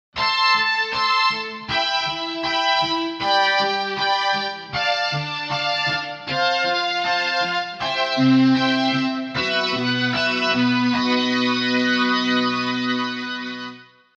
• Transforms the tone of your guitar or bass into that of a vintage synthesizer
Strings
SYNTH9Synthesizer-Machine-Strings.mp3